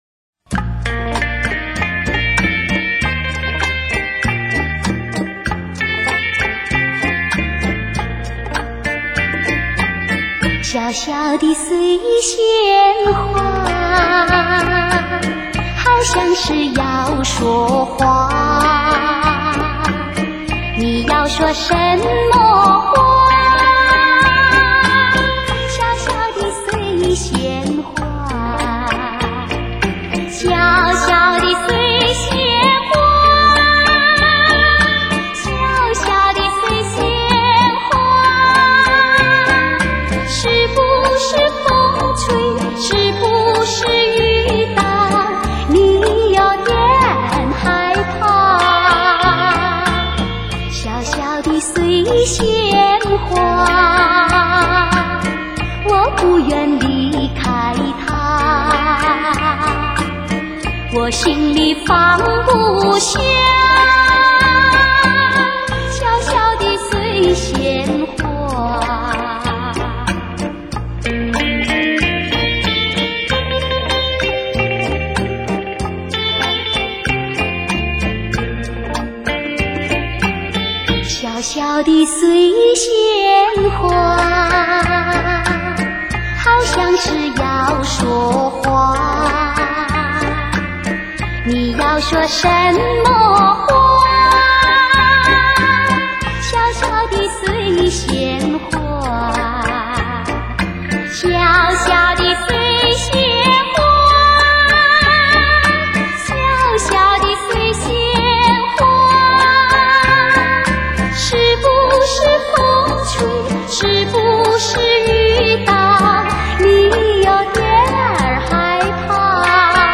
华语怀旧